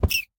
rabbit_hurt4.ogg